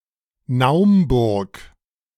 Naumburg (German: [ˈnaʊmbʊɐ̯k]
De-Naumburg.ogg.mp3